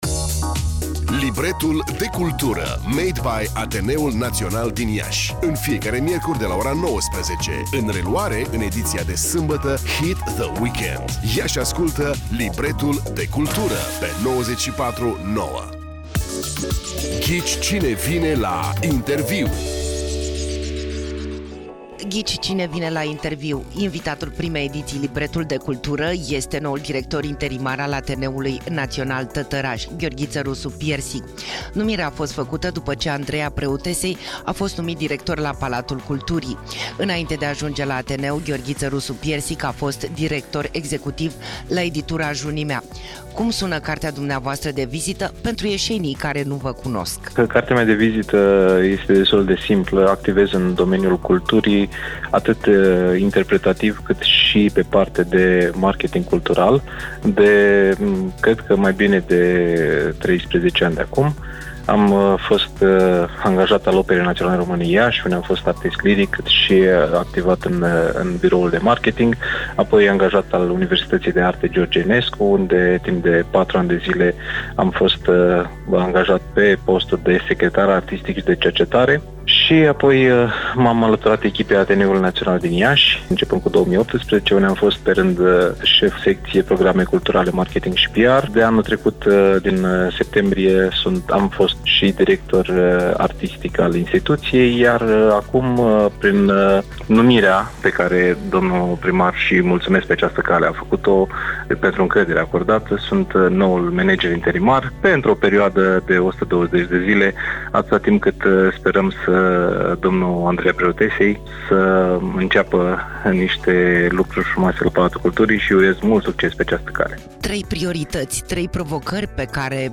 Care este viitorul bătrânelor cinematografe din Iași? Interviu - Radio Hit
Ia și ascultă Libretul de cultură pe 94,9. Ghici cine vine la interviu.